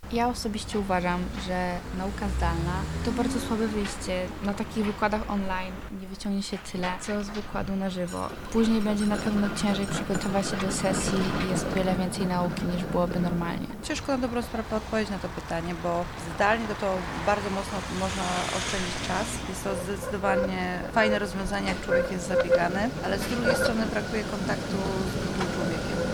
To część z myśli jakimi podzielili się słuchacze wrocławskich uczelni wyższych, z którymi rozmawialiśmy.
Powraca temat nauki zdalnej, która była charakterystyczna dla czasu pandemii, co może być związane z kosztami zakupu energii i nie tylko. Co o tym mówią sami studenci?